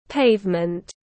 Pavement /ˈpeɪv.mənt/